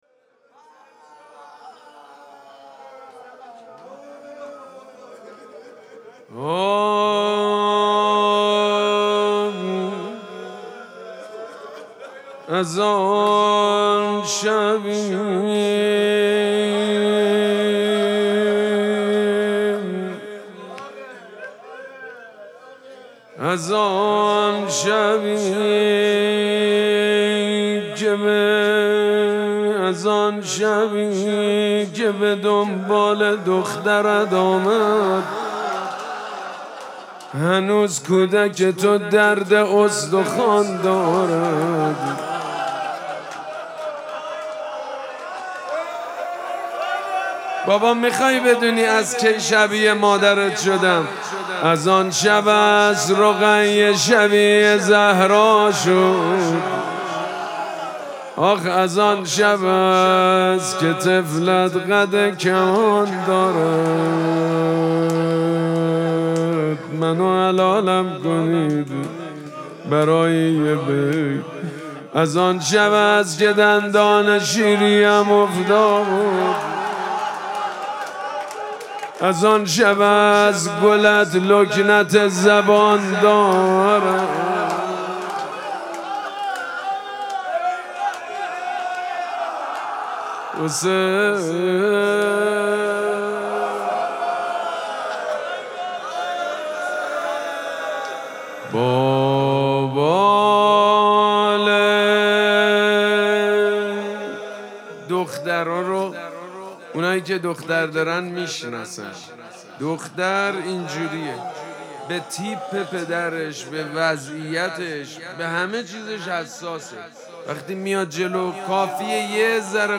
مراسم مناجات شب سوم ماه مبارک رمضان
روضه
حاج سید مجید بنی فاطمه